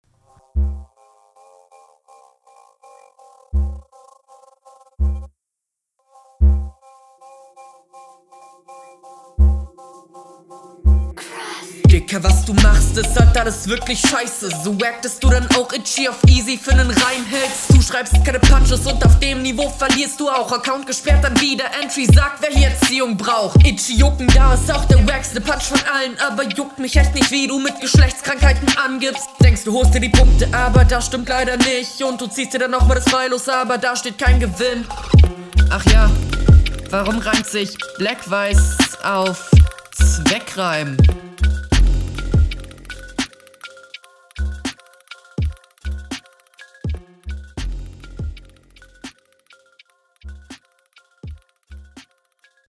Flow: Stabil durchgezogen, Bounced auf jedenfall gut, Shuffles könnten n tick cleaner sein, aber passt …